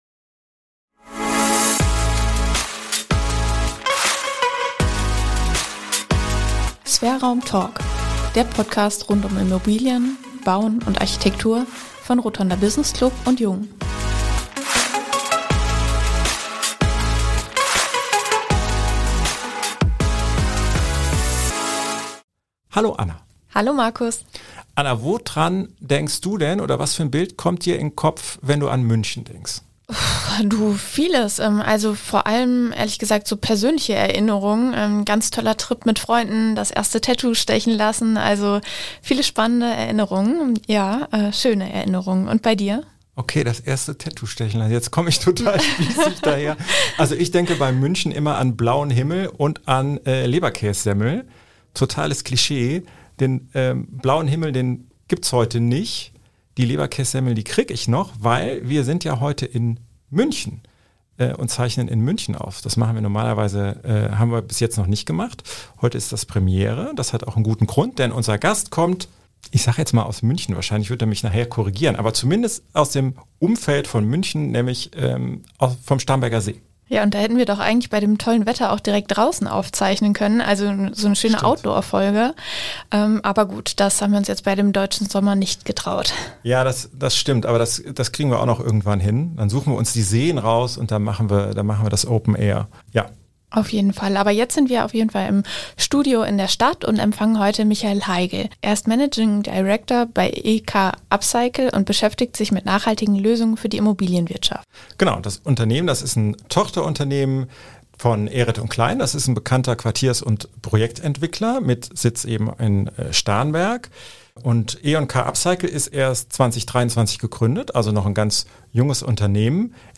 - Desktop-Analysen reichen nicht - man muss immer vor Ort gehen. Ein kurzweiliges Gespräch über Heimat, Leberkäs-Semmeln, ein richtungsweisendes Projekt in Athen (und nicht Warschau!) und Smart Low Tech Mehr